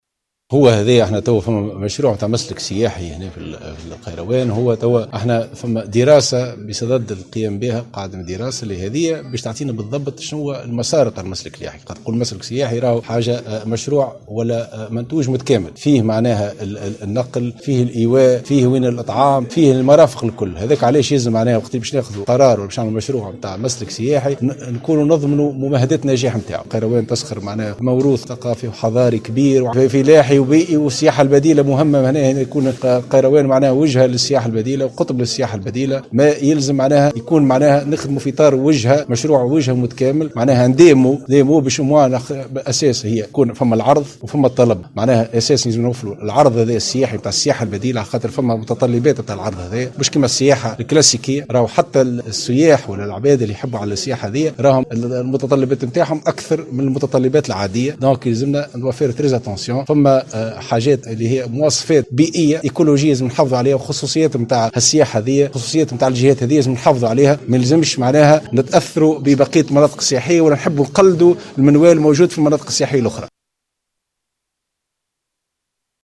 وزير السياحة : محمد المعز بلحسين